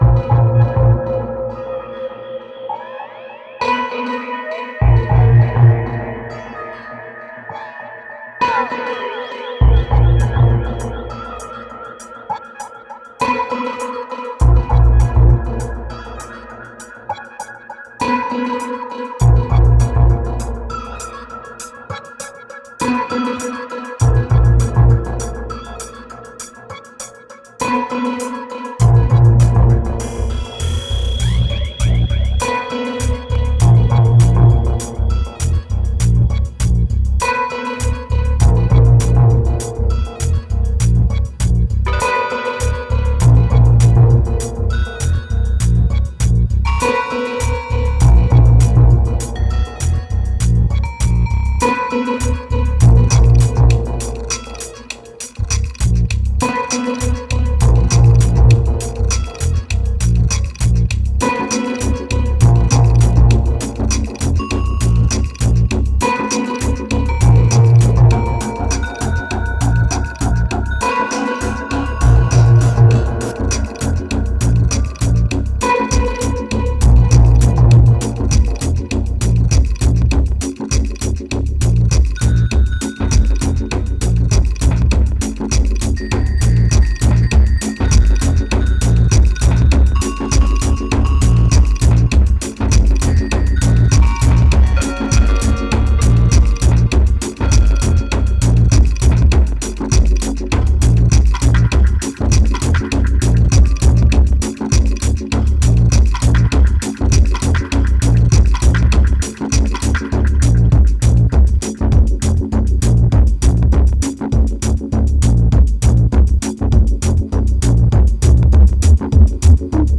2256📈 - 26%🤔 - 100BPM🔊 - 2011-10-21📅 - -42🌟